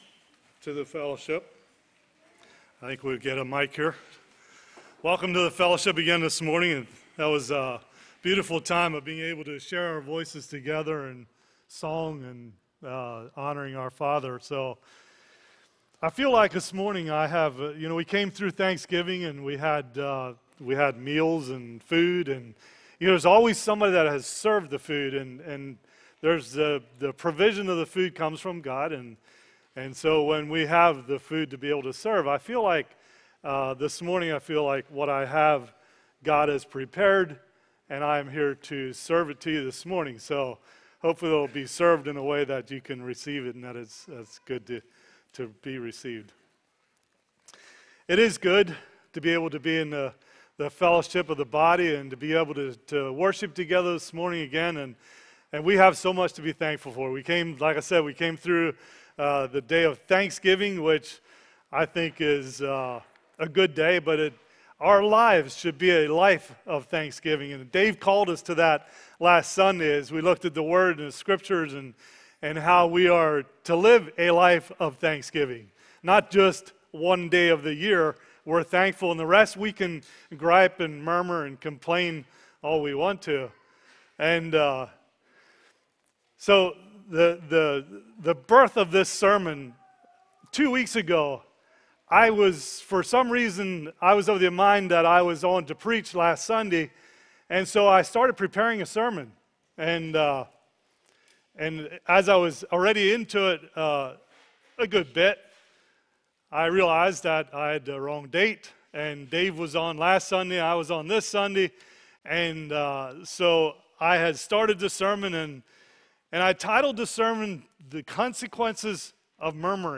Sermon Archive | - New Covenant Mennonite Fellowship
From Series: "Sunday Morning - 10:30"